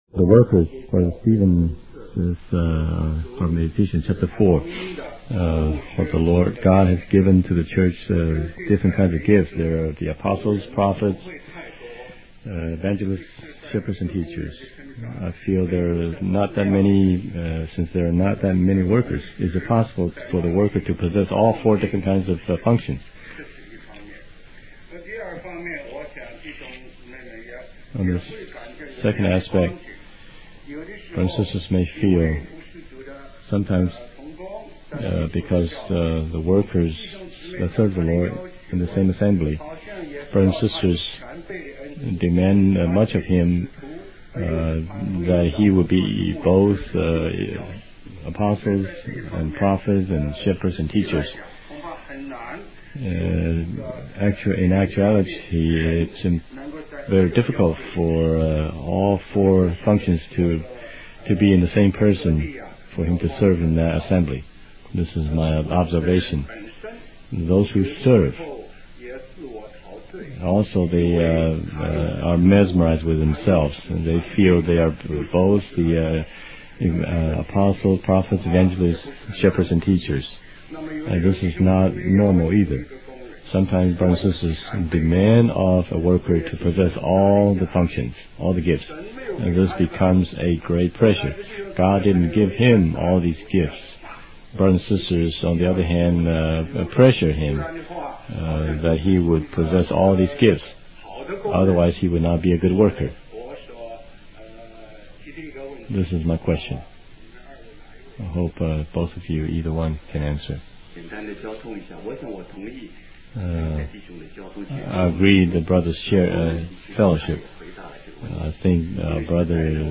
Question and Answer